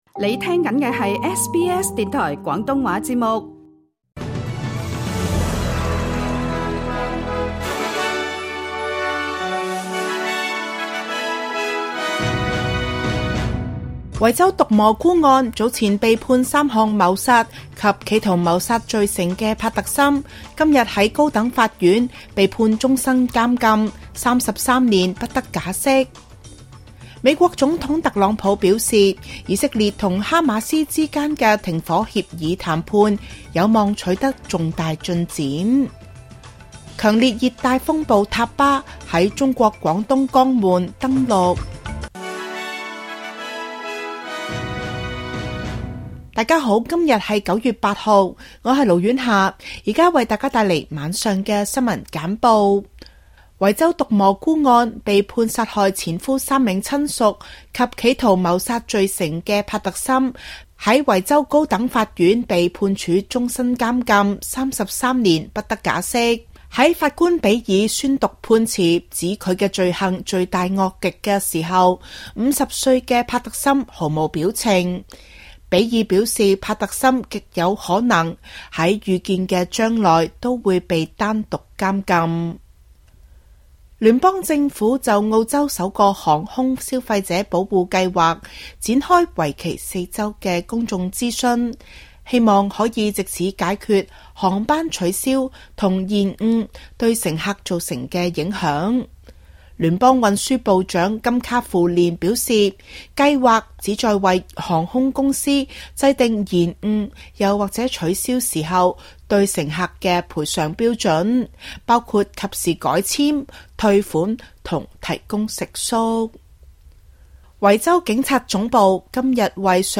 SBS晚間新聞（2025年9月8日）
請收聽本台為大家準備的每日重點新聞簡報。